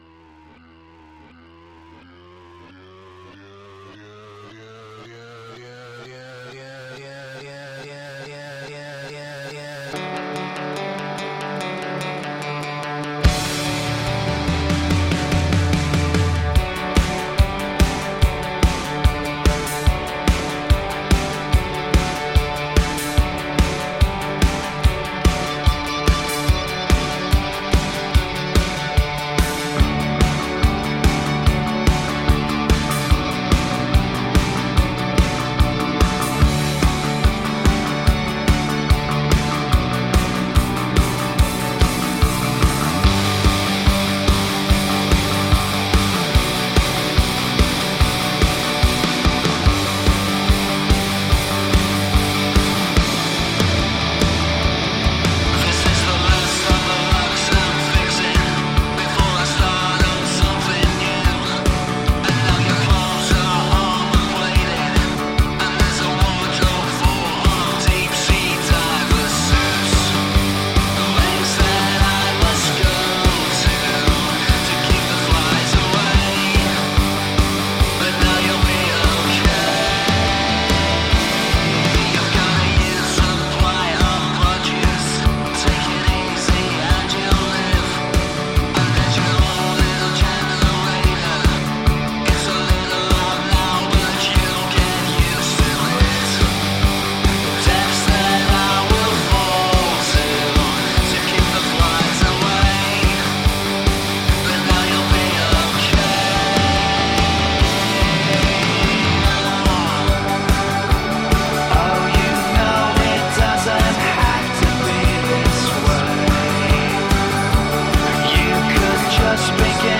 Shimmering indie guitar pop with orchestral moments.